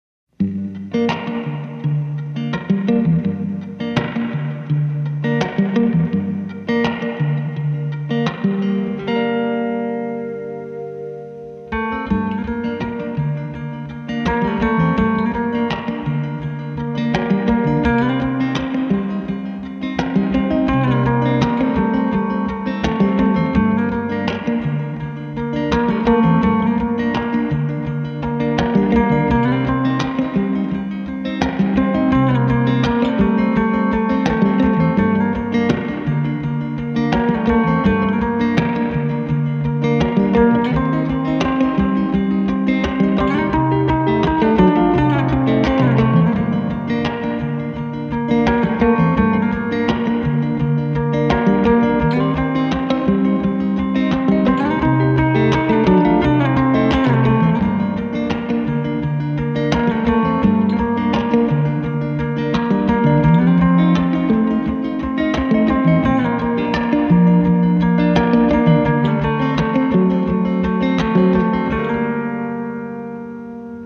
• Качество: 256, Stereo
гитара
спокойные
без слов
струнные
инструментальные
успокаивающие
Красивая игра на гитаре